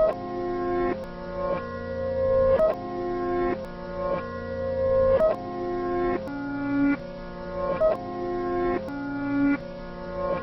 CL Sample B (92bpm).wav